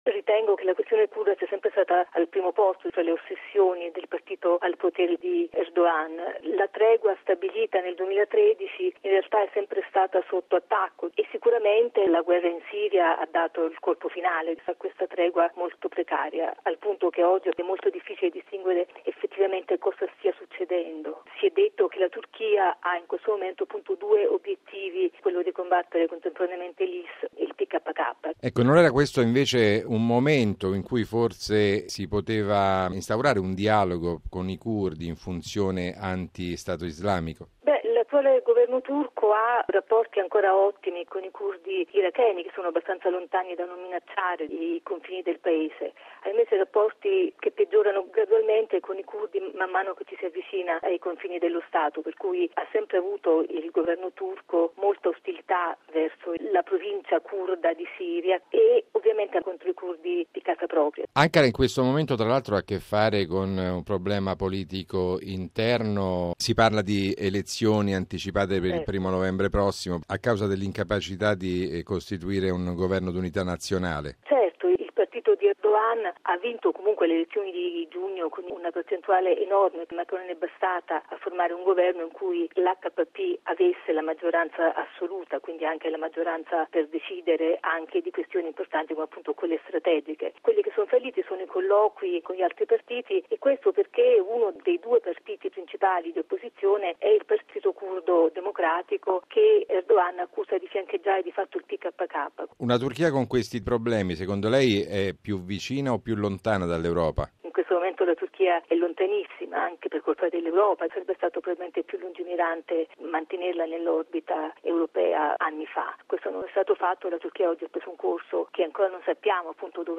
Logo 50 Radiogiornale Radio Vaticana